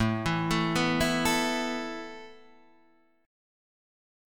A Suspended 2nd